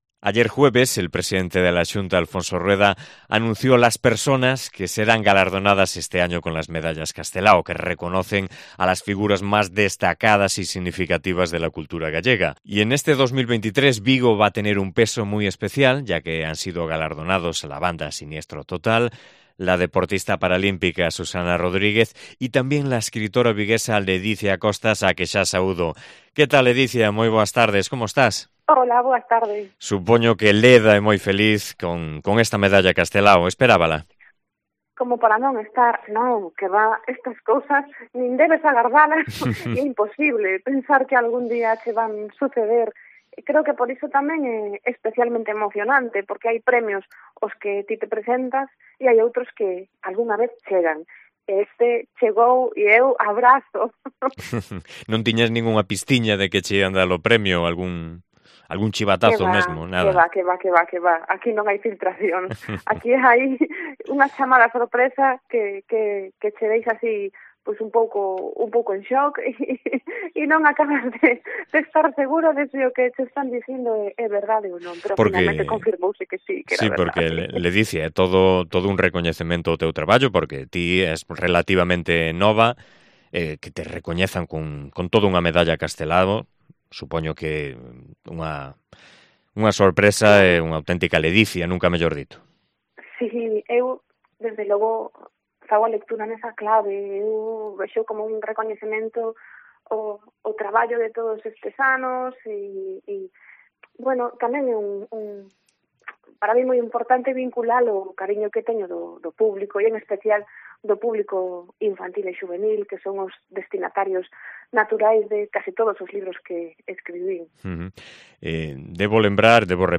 Entrevista á escritora Ledicia Costas, nova Medalla Castelao